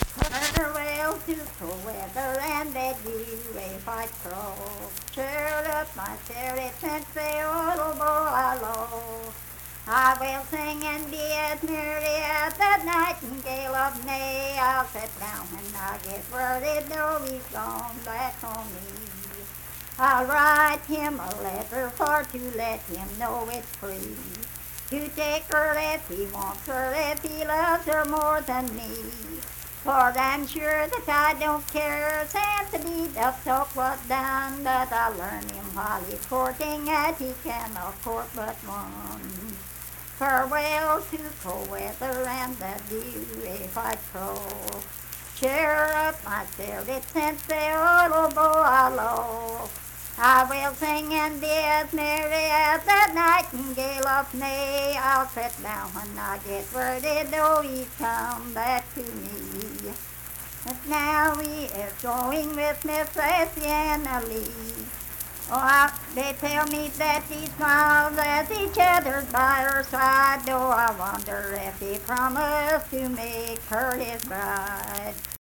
Unaccompanied vocal music performance
Verse-refrain 2(4).
Voice (sung)